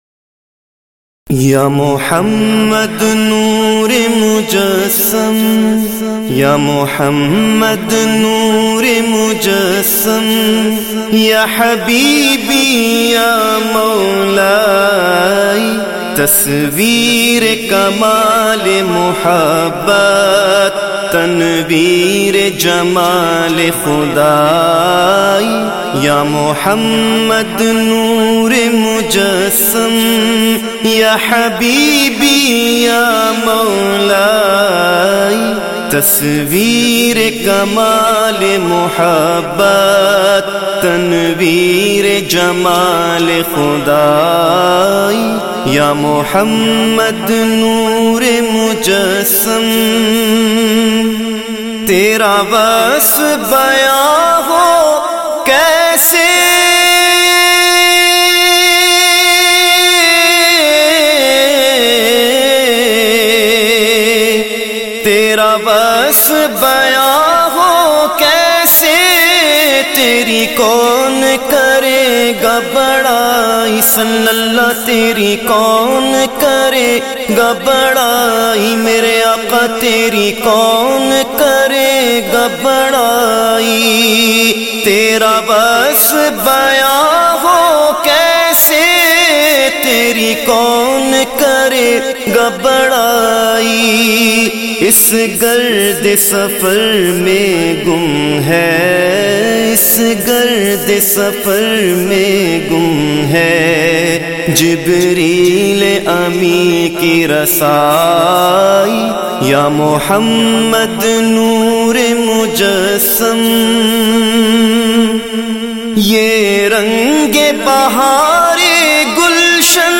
The Naat Sharif Ya Muhammad Noor e Mujassam